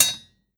Blacksmith hitting hammer 2.wav